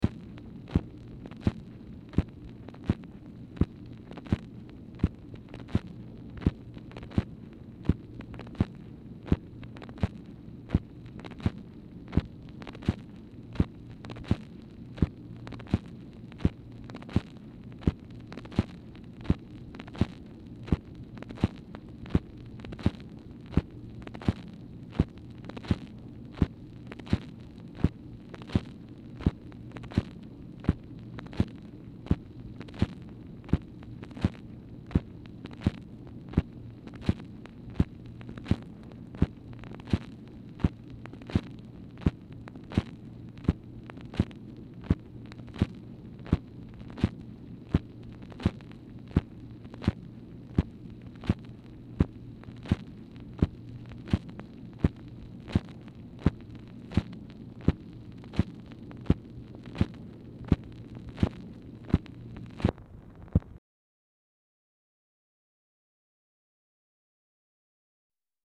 Telephone conversation # 8869, sound recording, MACHINE NOISE, 9/14/1965, time unknown | Discover LBJ
Format Dictation belt
Location Of Speaker 1 Oval Office or unknown location
Specific Item Type Telephone conversation